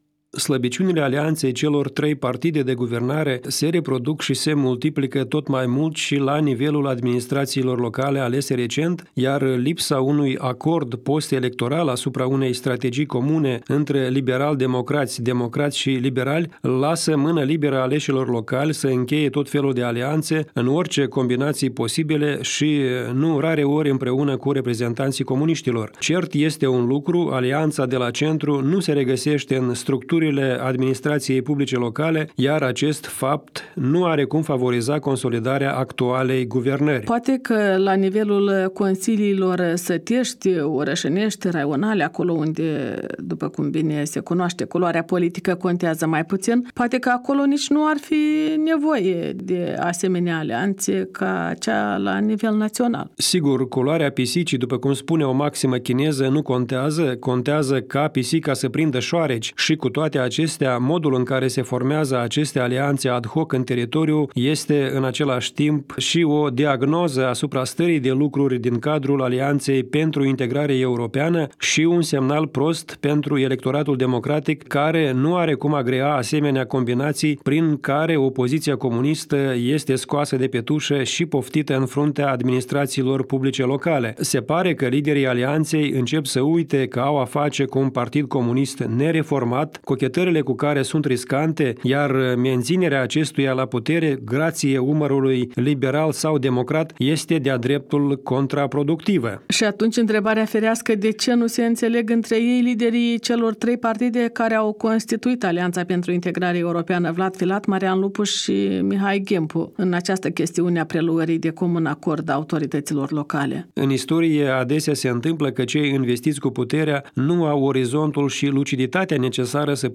Intervievat